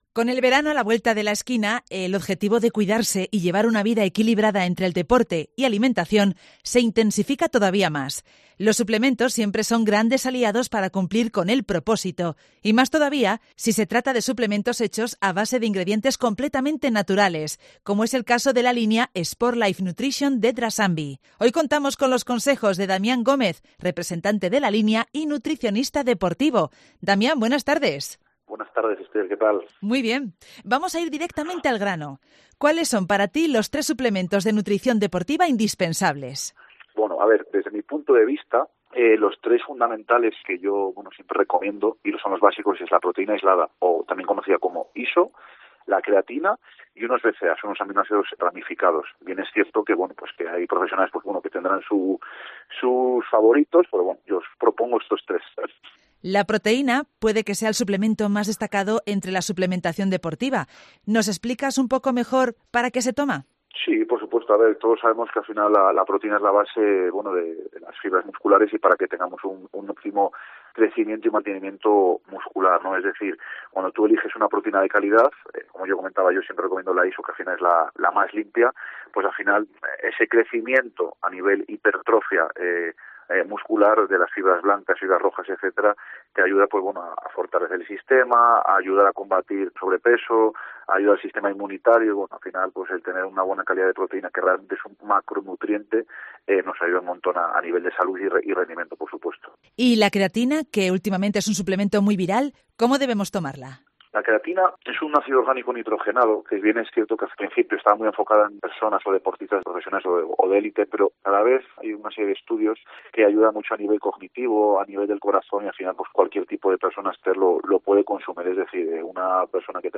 Hoy en Mediodía en COPE León, contamos con los consejos